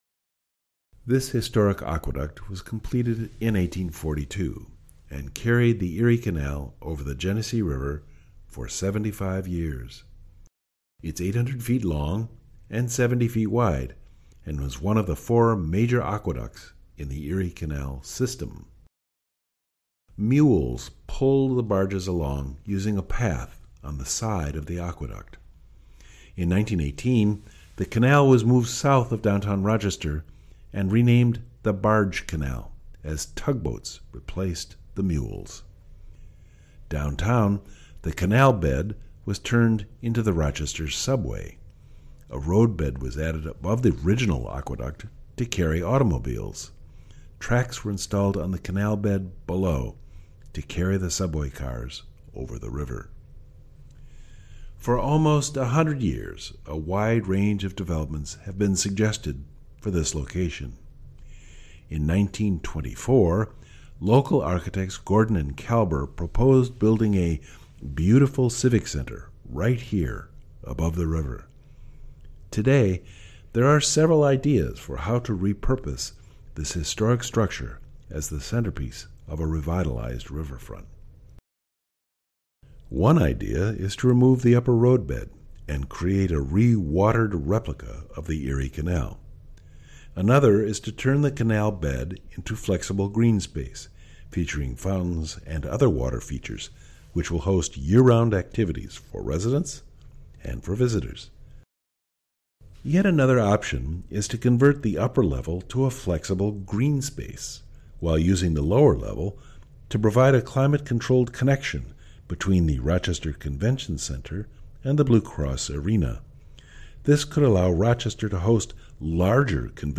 This narrated walking tour begins at Corn Hill Landing Park and heads north along the river trail.  You’ll cross several historic bridges, including the aqueduct which once carried the Erie Canal over the Genesee River.